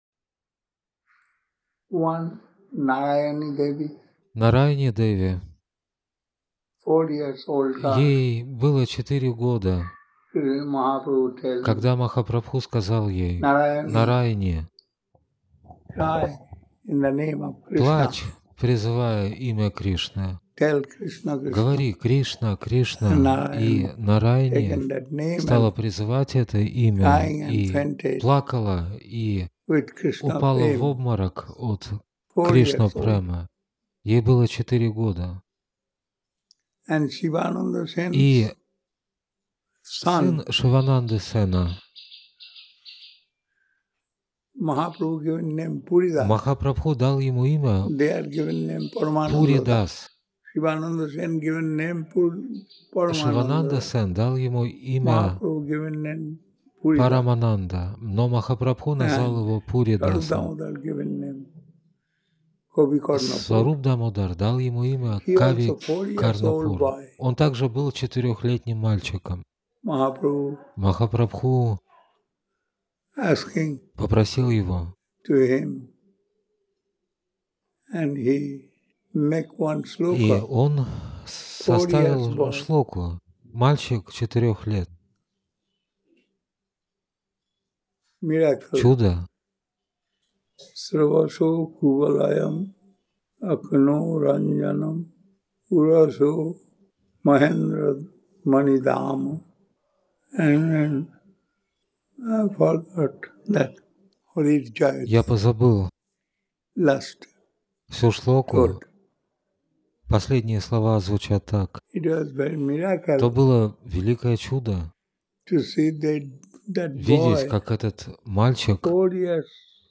Навадвипа Дхама, Индия